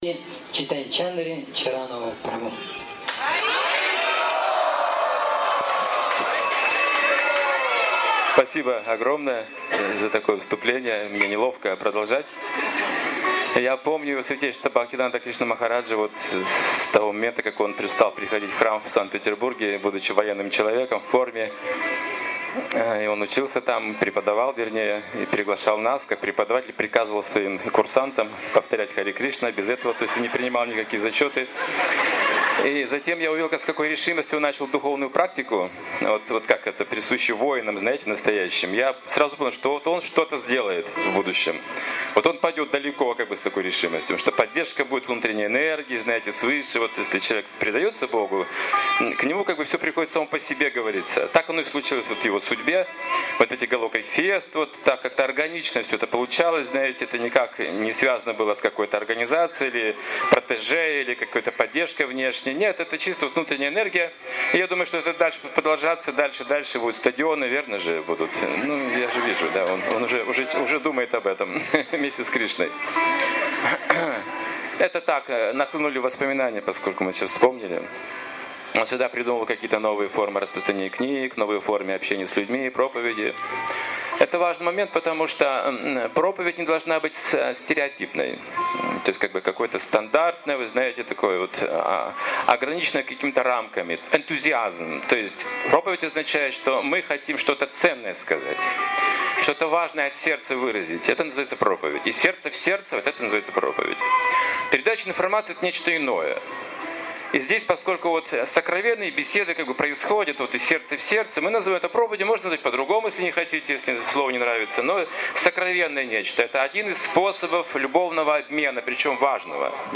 Голока Фест. Из сердца в сердце (2013, Москва)
Лекция посвящена духовной науке.